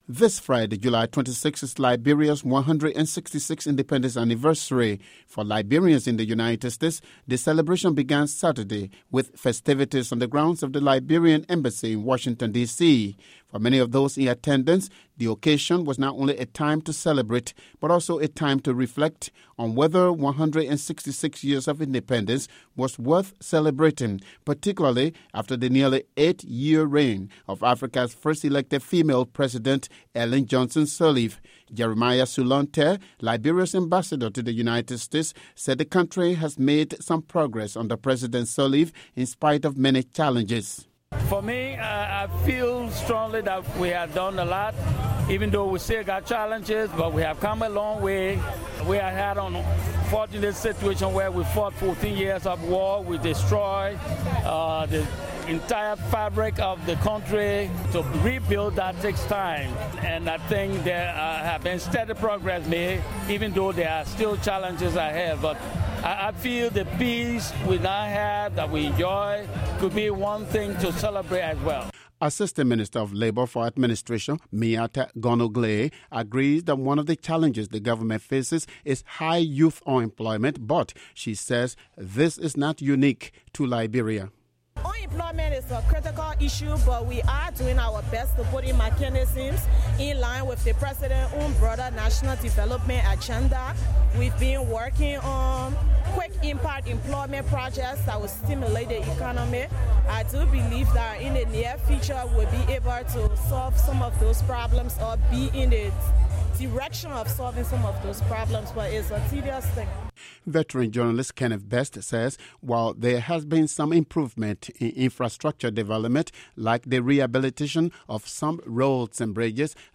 For Liberians in the United States, the celebration began Saturday with festivities on the grounds of the Liberian Embassy in Washington.